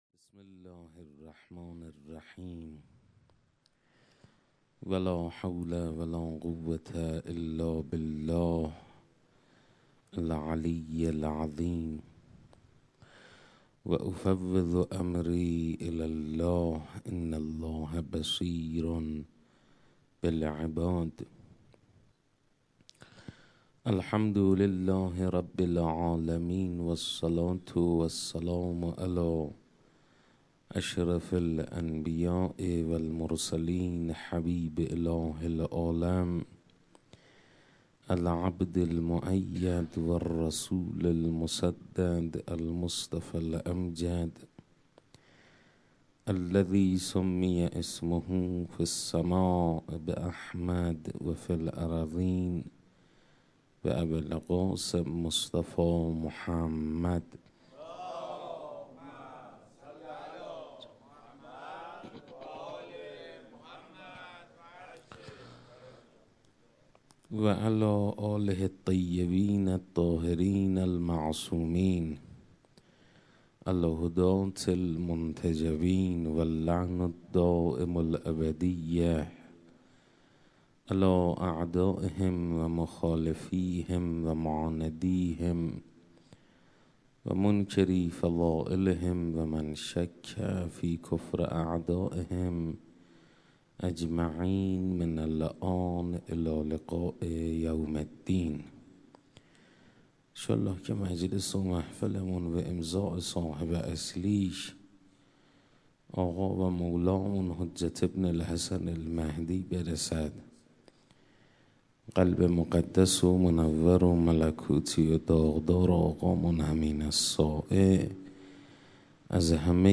حسینیه انصارالحسین علیه السلام